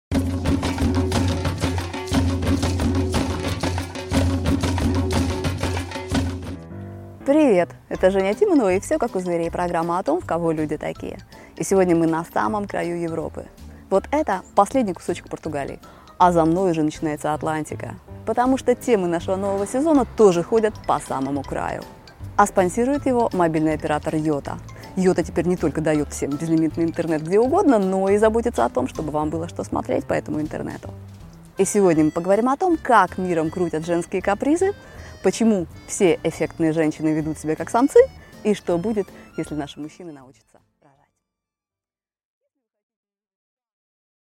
Аудиокнига Половой отбор: чего хотят женщины | Библиотека аудиокниг